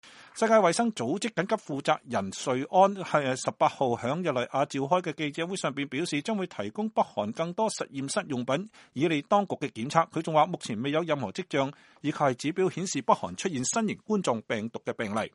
世界衛生組織(WHO)緊急計劃負責人瑞恩(Mike Ryan)18日在日內瓦召開的記者會上表示，將提供北韓更多實驗室用品以利當局檢測，他還說，目前沒有任何跡象及指標顯示北韓出現新型冠狀病毒病例。